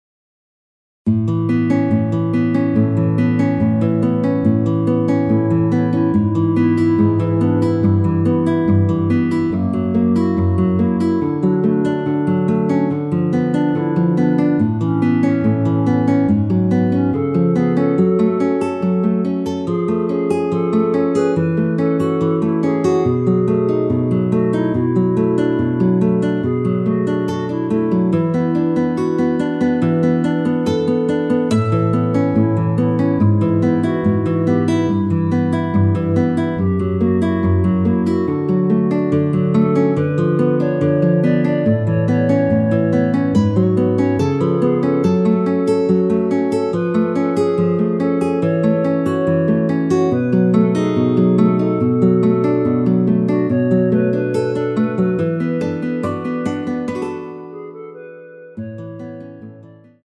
원키 멜로디 포함된 MR 입니다.
앞부분30초, 뒷부분30초씩 편집해서 올려 드리고 있습니다.
중간에 음이 끈어지고 다시 나오는 이유는